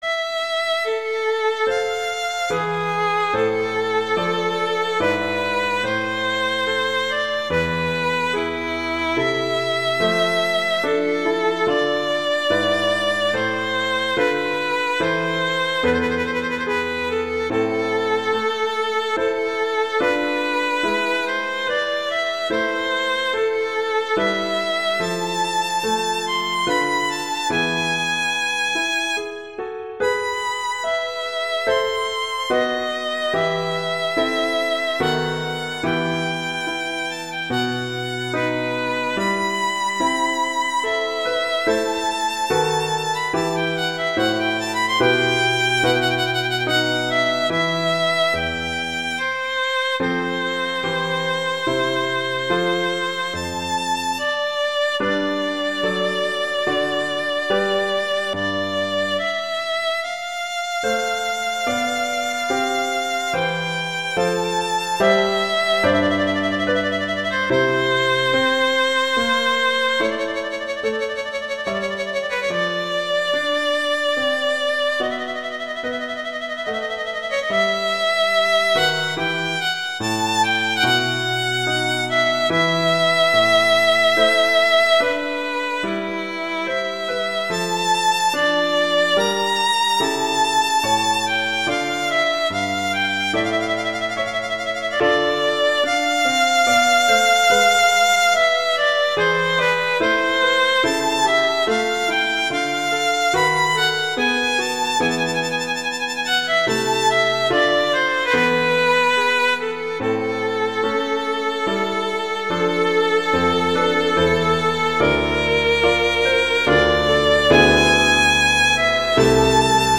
violin and piano
classical
Adagio non troppo